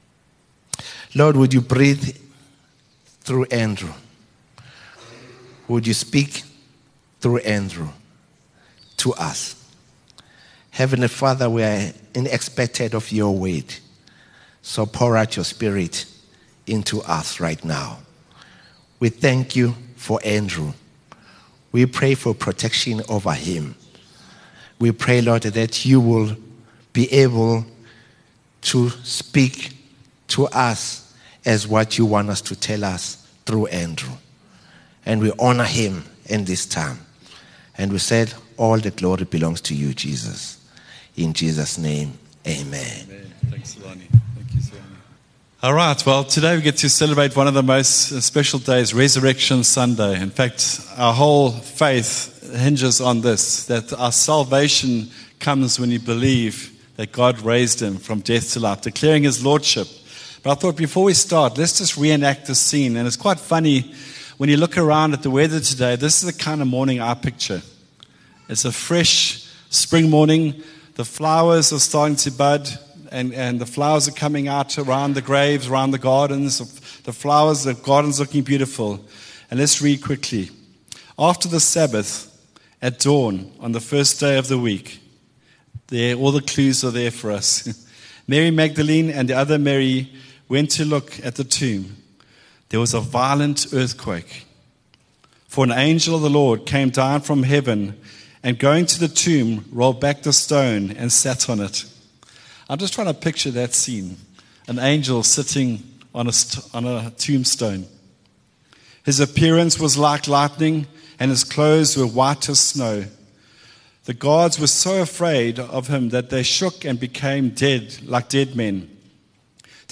Upper Highway Vineyard Sunday messages
Sunday sermon